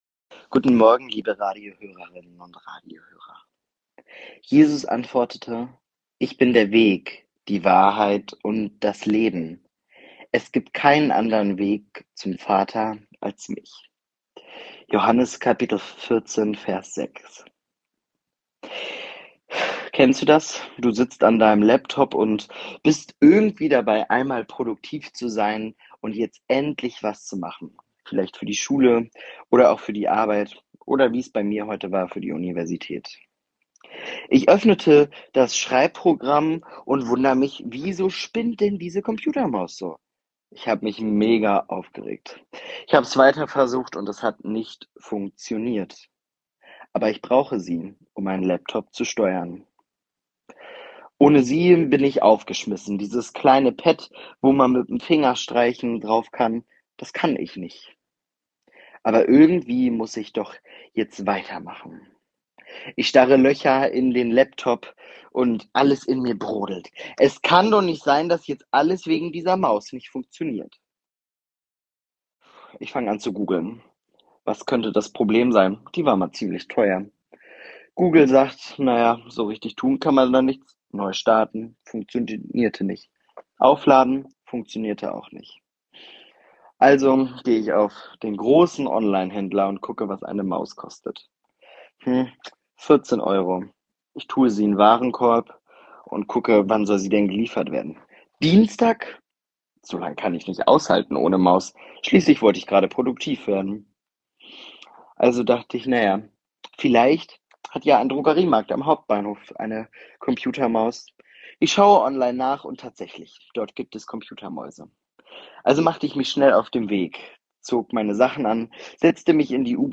Radioandacht vom 25. September